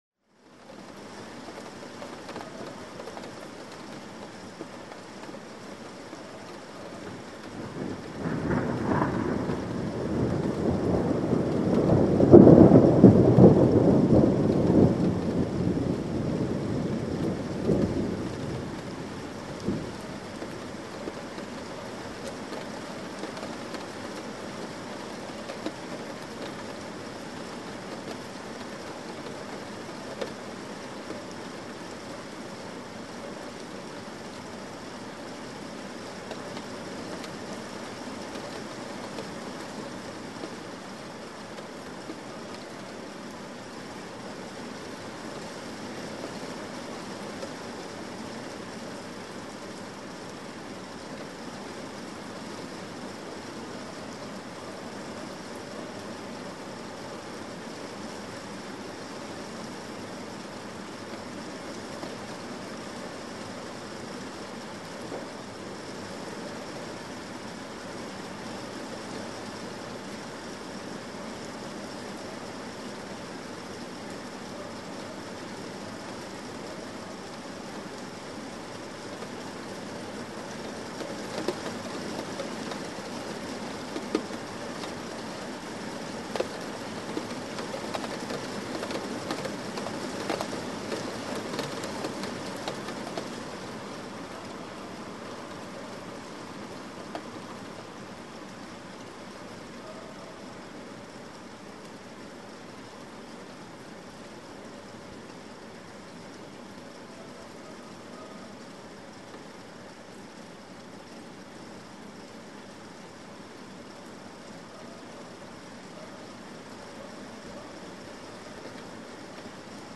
Звуки лета
Звуки природы: летний гром, дождь в загородном поселке